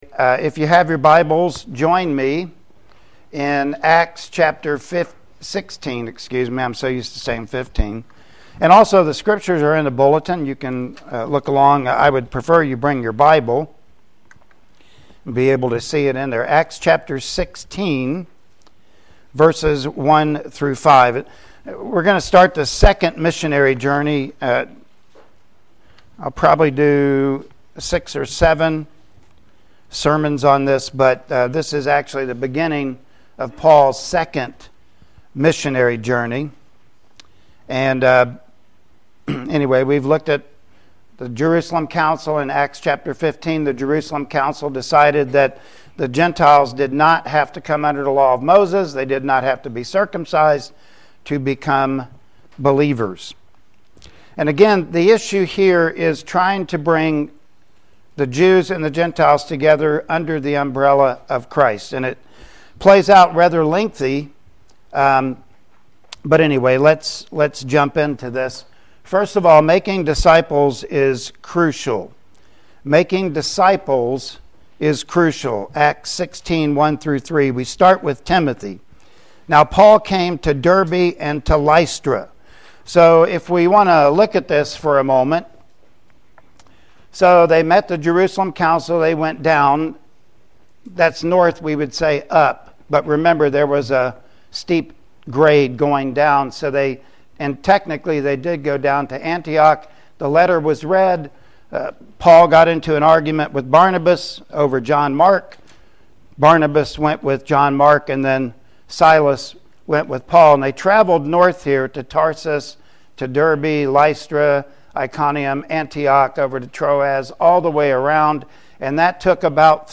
Acts 16:1-5 Service Type: Sunday Morning Worship Service Topics: Commitment